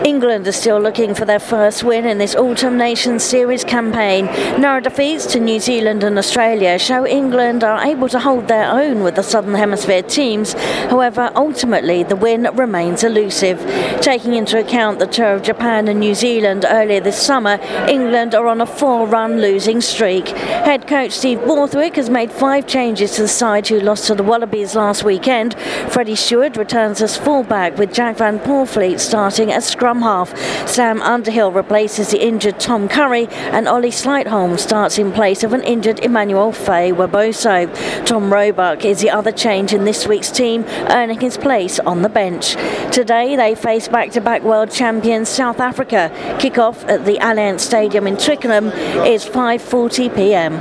at the Allianz Stadium in Twickenham